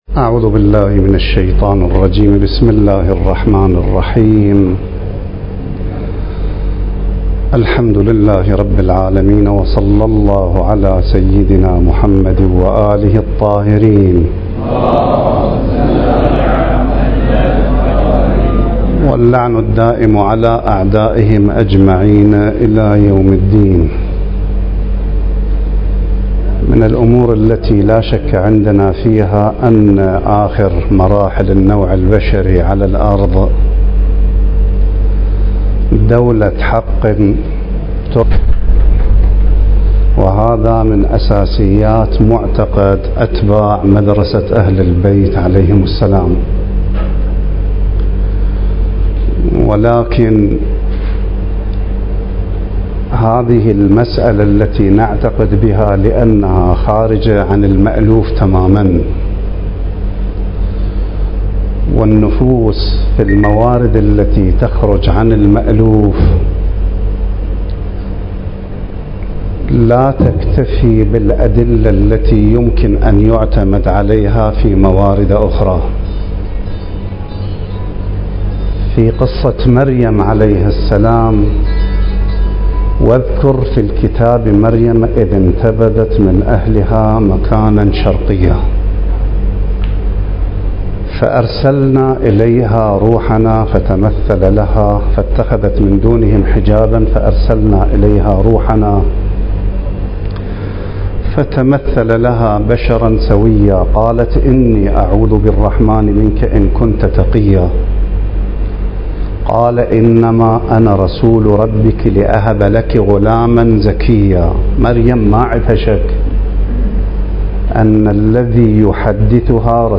المكان: جامعة الإمام المهدي (عجّل الله فرجه) النموذجية للدراسات الدينية - النجف الأشرف ندوة علمية بعنوان (مساهمة الأمل بقرب الظهور في ثبات المؤمنين) التاريخ: 2022